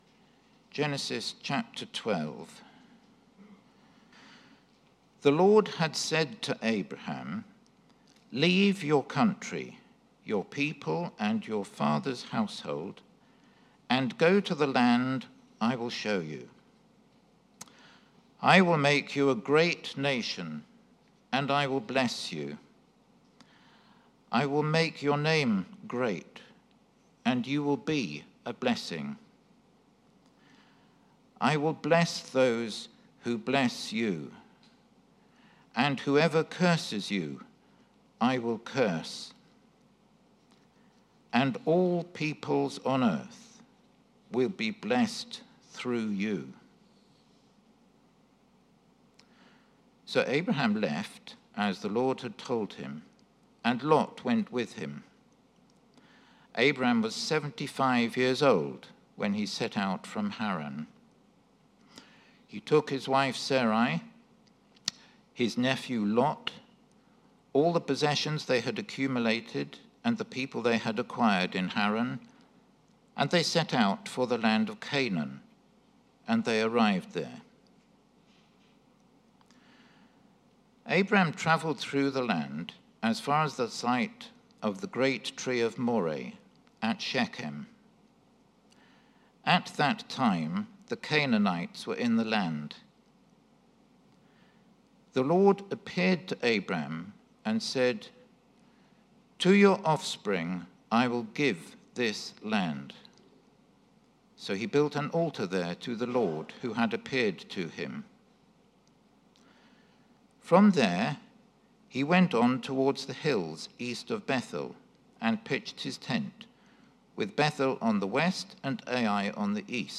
Media for Sunday Service